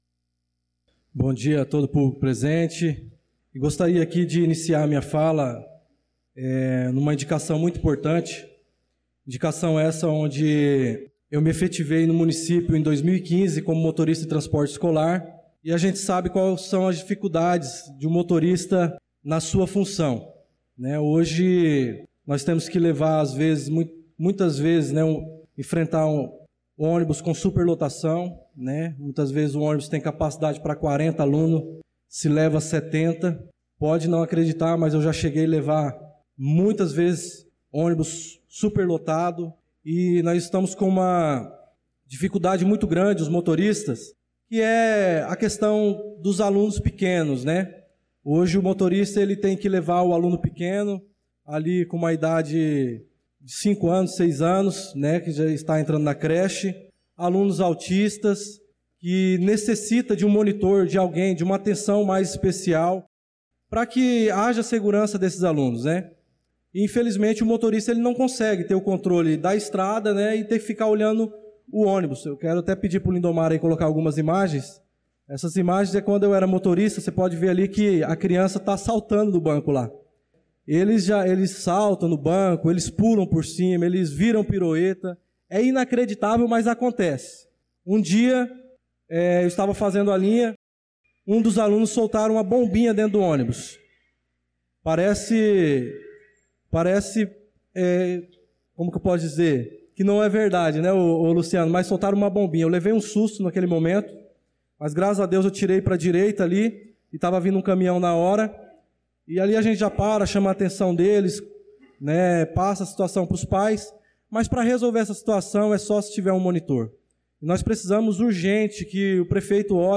Pronunciamento do vereador Darlan Carvalho na Sessão Ordinária do dia 25/02/2025